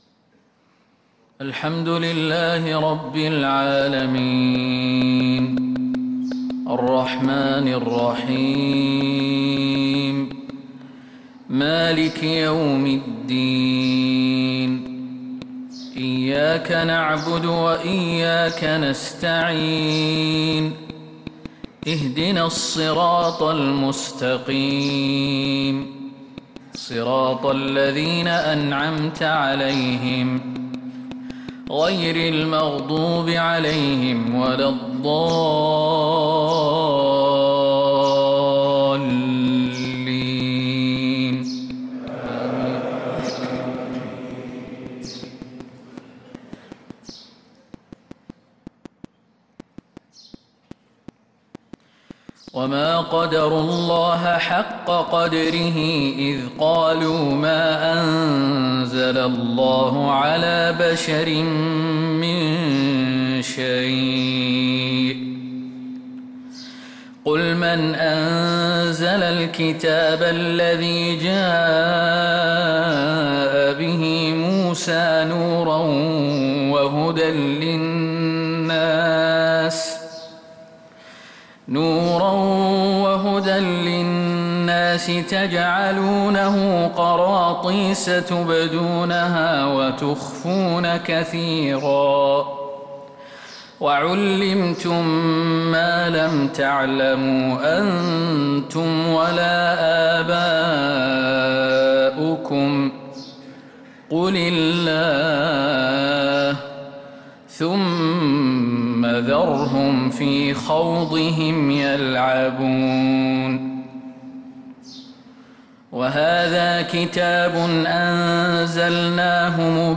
فجر الأربعاء 1-3-1446هـ من سورة الأنعام | Fajr prayer from Surat Al-An'aam 4-9-2024 > 1446 🕌 > الفروض - تلاوات الحرمين